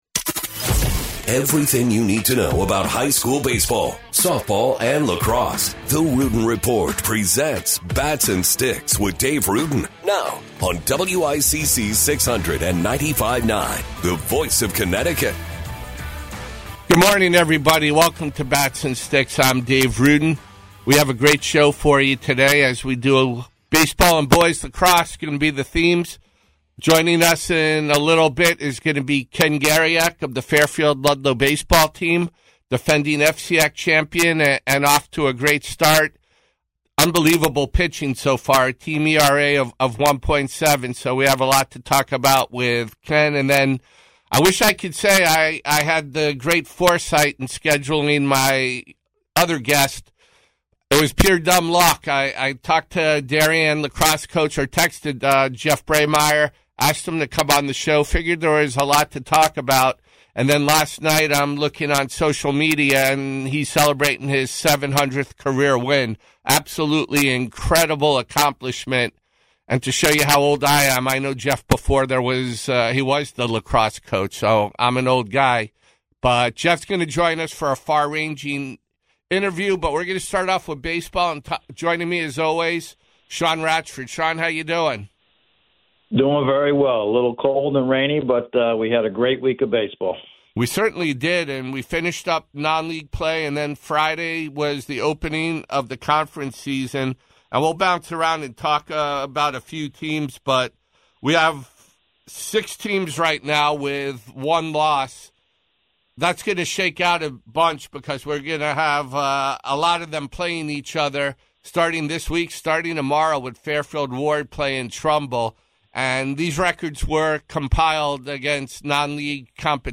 wide-ranging interview